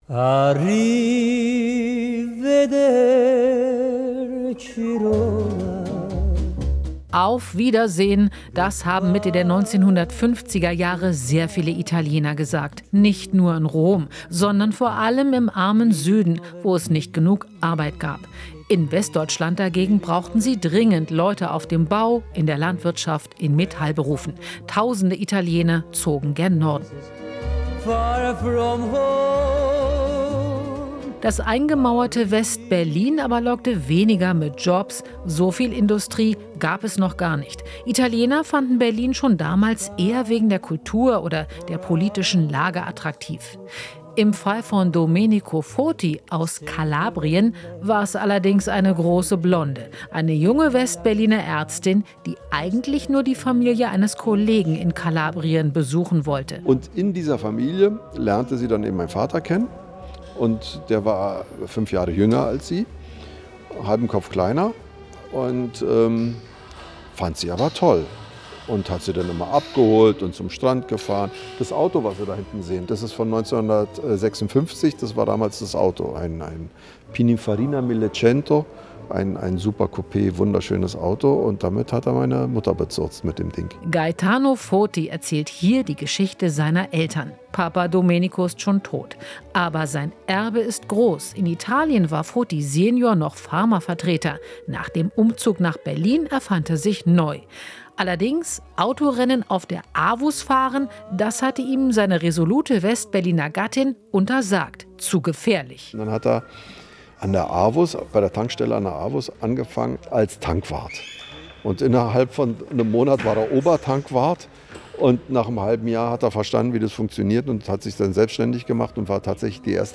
Alles was wichtig ist in der Hauptstadtregion - in Interviews, Berichten und Reportagen.